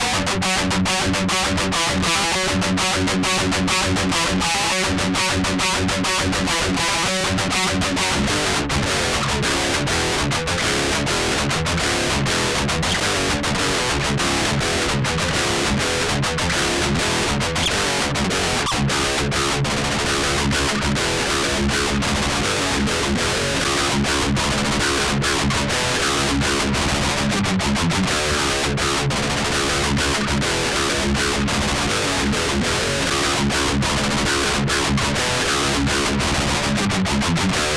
����� ������� SOLDANO-100 �� Max-sound (Lead ����� - ������)